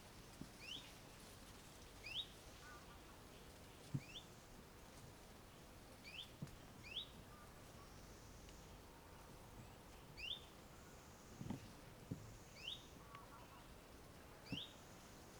1. Downy Woodpecker (Dryobates pubescens)
Call: A sharp “pik” and descending whinny-like trill.